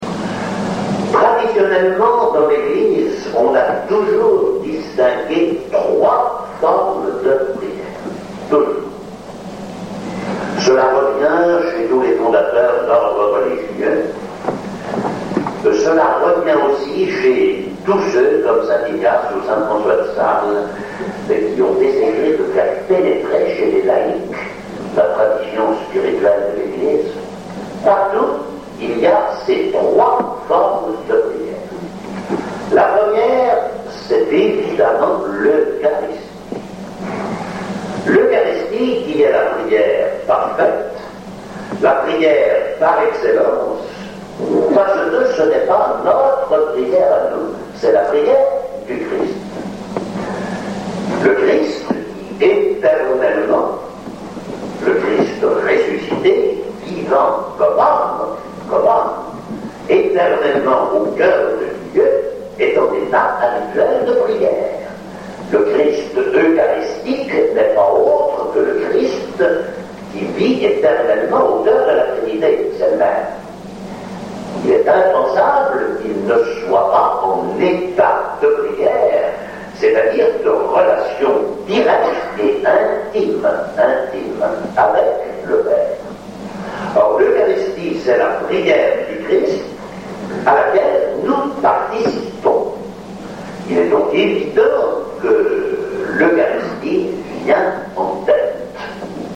Extraits d’une conférence